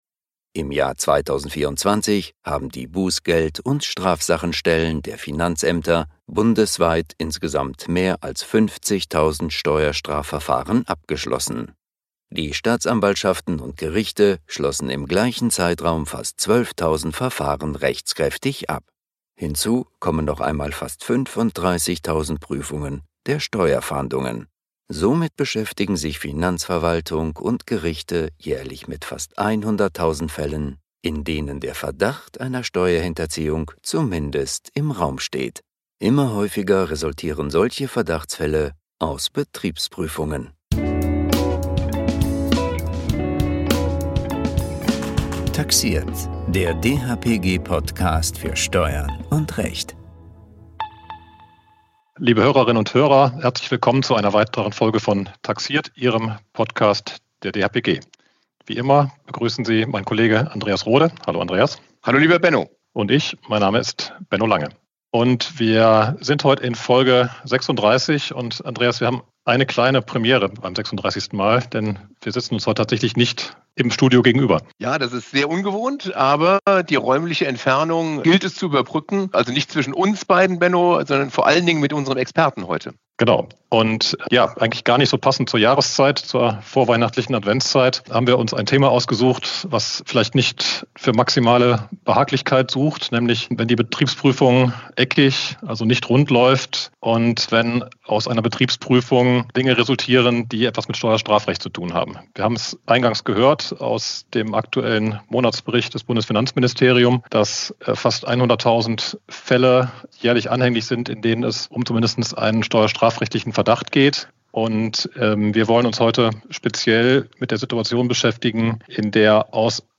Immer häufiger resultieren solche Verdachtsfälle aus Betriebsprüfungen. Im dhpg-Podcaststudio sprechen die beiden Moderatoren